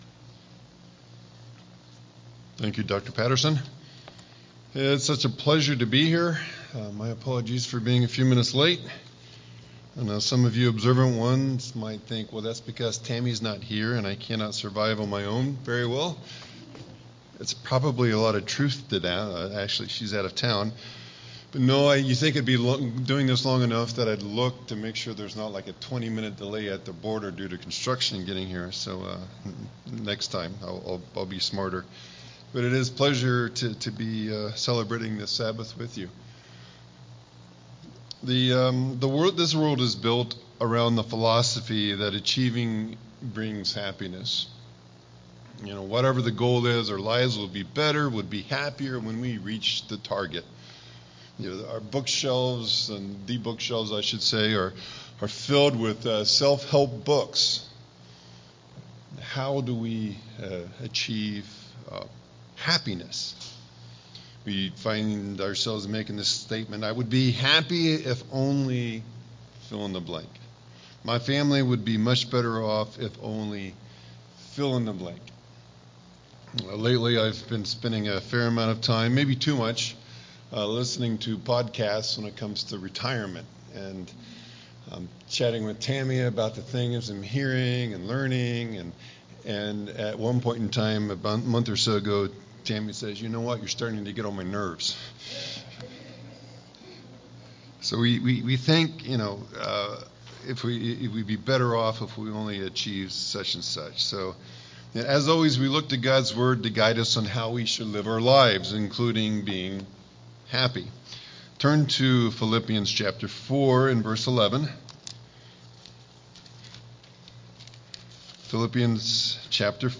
In this sermon, the speaker emphasized the concept of contentment, using Philippians 4:11 as a foundation. He discussed how the world often equates happiness with achievement, leading to constant striving and dissatisfaction. He highlighted that true contentment, as described by Paul, involves being sufficient and independent of external circumstances.